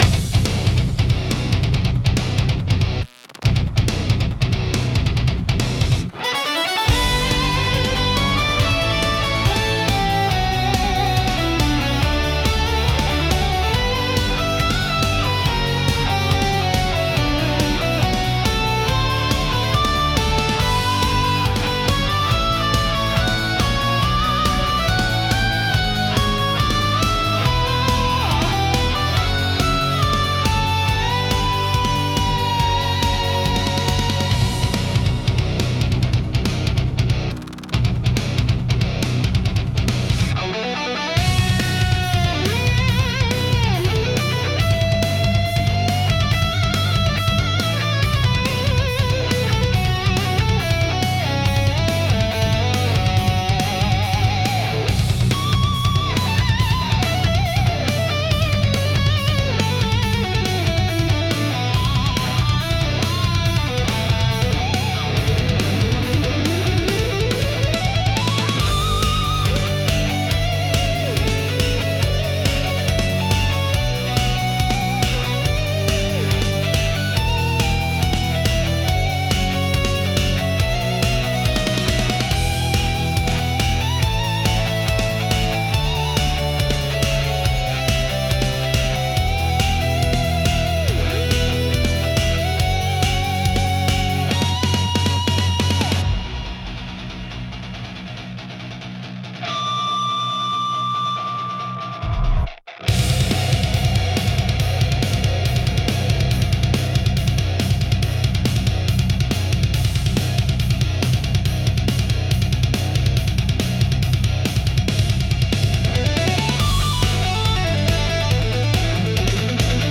Genre: Epic Mood: Video Game Editor's Choice